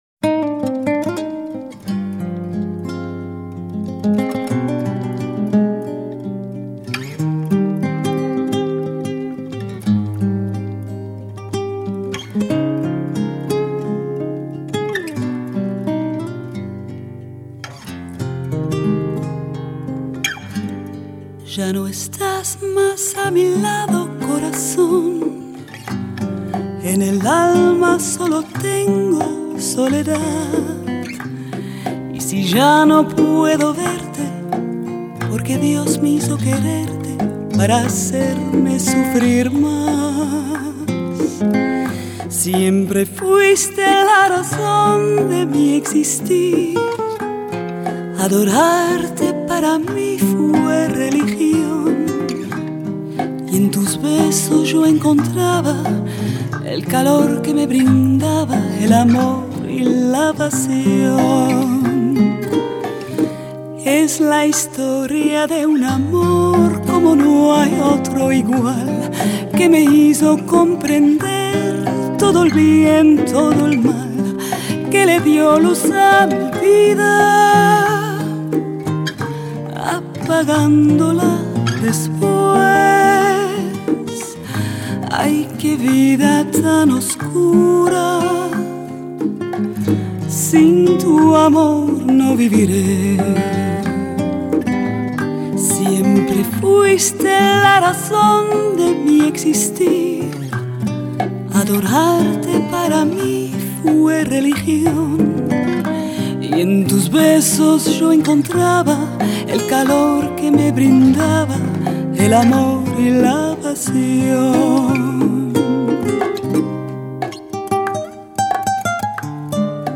拉丁天后
音色更接近模拟(Analogue)声效
强劲动态音效中横溢出细致韵味